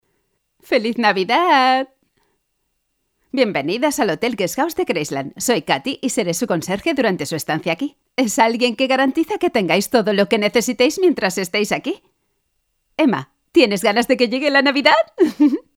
Actrices de doblaje españolas.
doblaje, voice over actor.